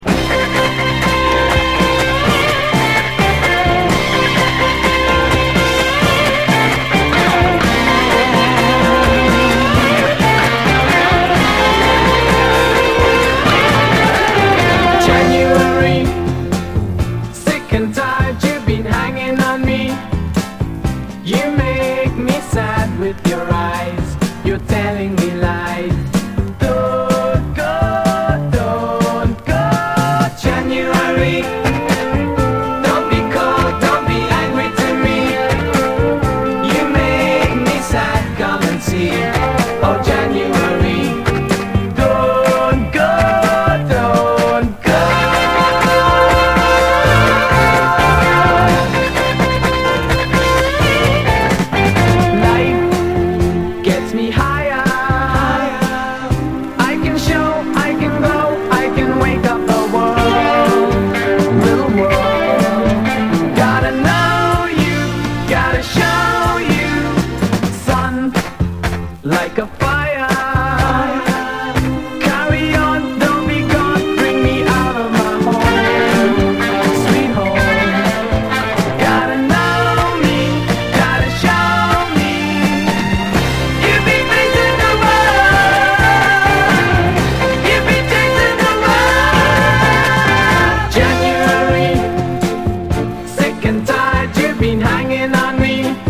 70's ROCK, SSW / AOR, ROCK, 7INCH
万人に愛される甘酸っぱいメロディーとハーモニーの胸キュン・ポップ名曲！